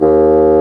Index of /90_sSampleCDs/Roland LCDP04 Orchestral Winds/WND_Bassoons/WND_Bassoon 4
WND BSSN D#2.wav